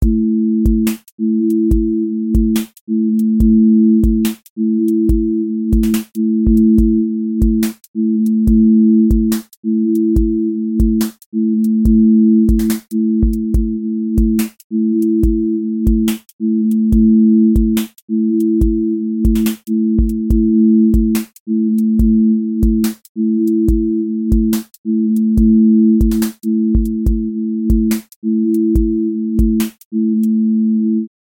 QA Listening Test drill Template: drill_glide
Drill glide tension with sliding low end